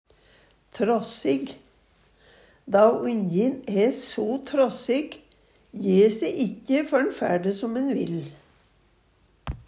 tråssig - Numedalsmål (en-US)